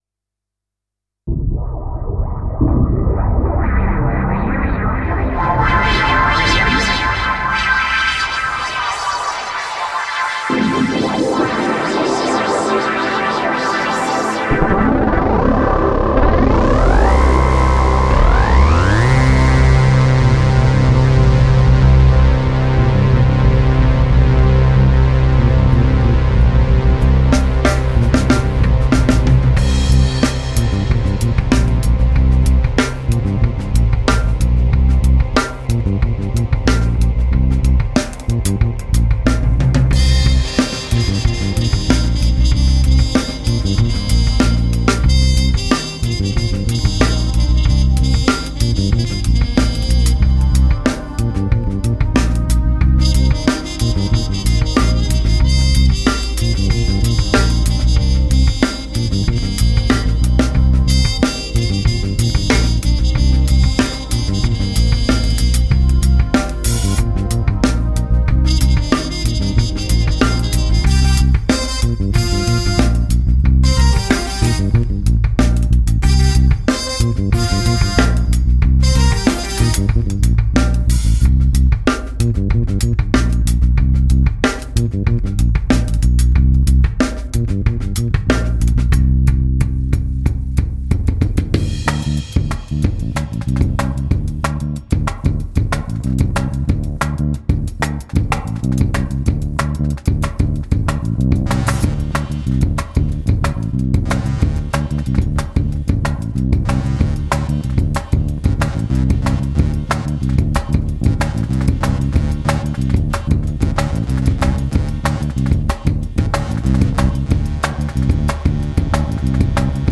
Žánr: Jazz/Blues
mixující moderní taneční rytmy s jazzovou hudbou.